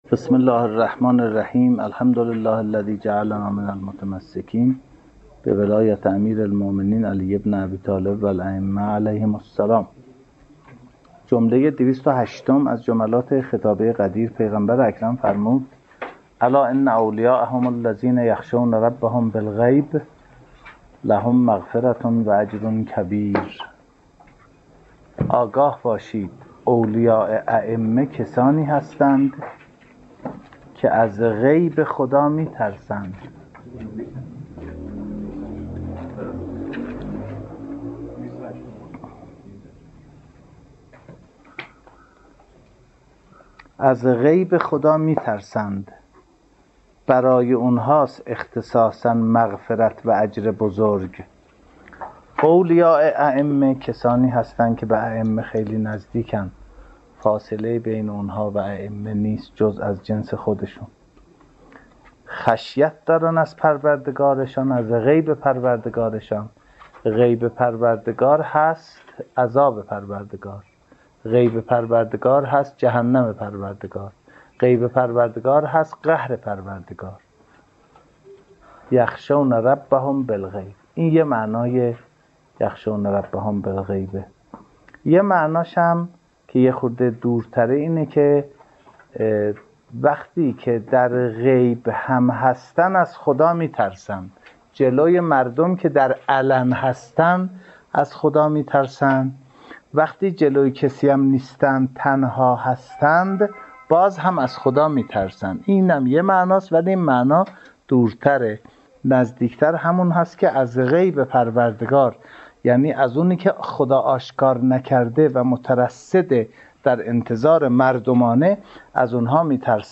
آرشیو سخنرانی‌های خطابه‌ی غدیر